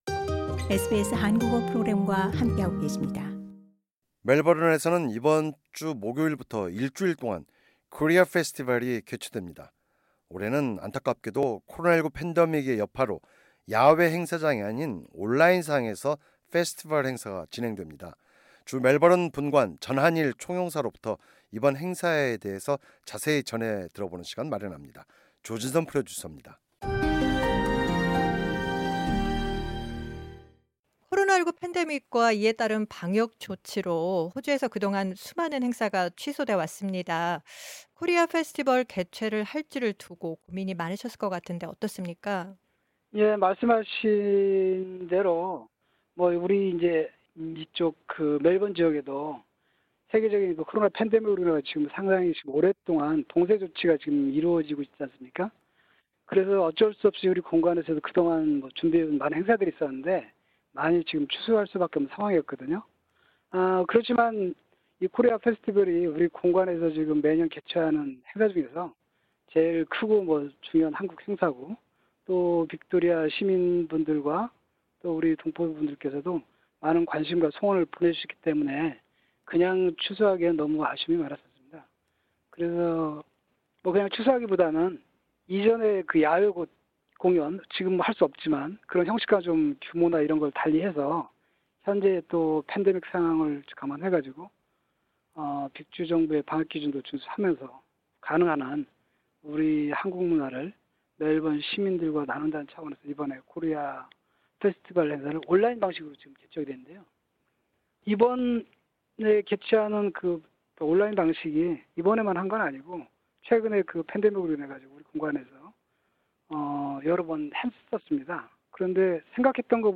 "대담-전한일 총영사"...2020 멜버른 코리아 페스티벌, 온라인에서 새 지평연다